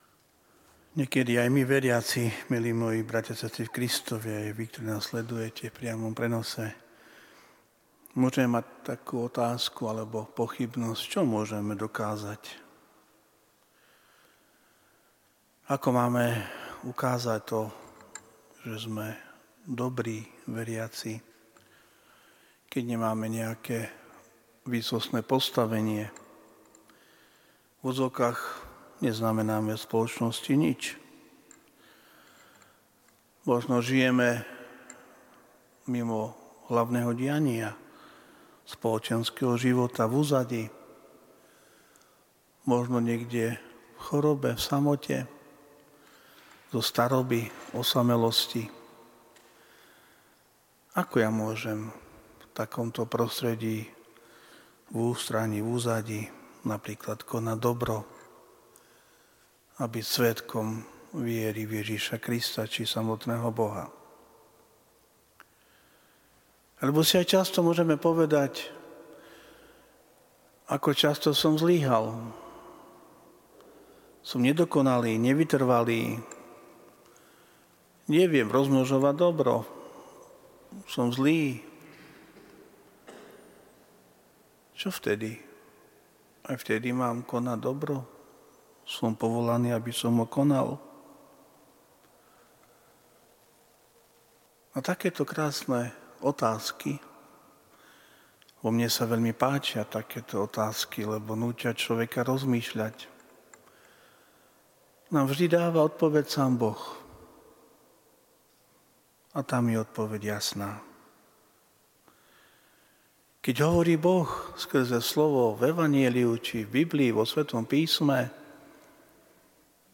Podcasty Kázne AKO UKÁZAŤ NAŠU VIERU?